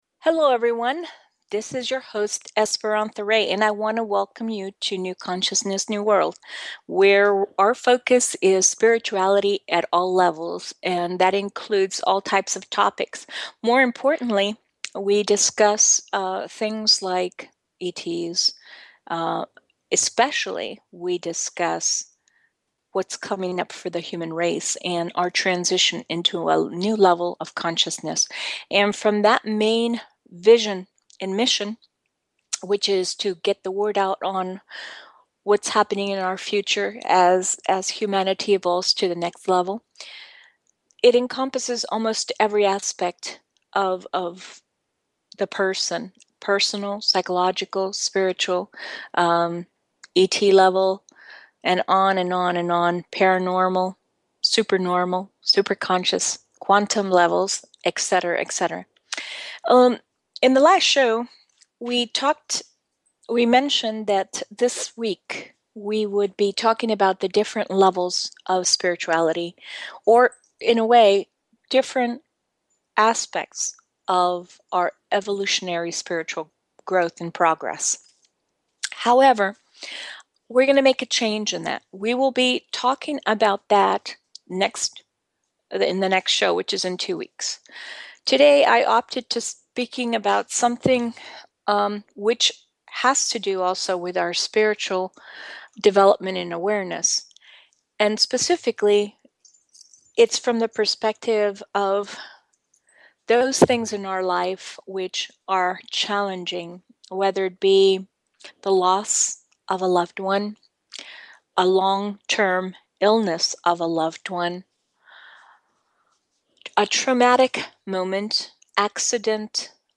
This will be accomplished through a series of discussion, interviews with other experts, teaching techniques to help in this process, and audience participation through questions and answers.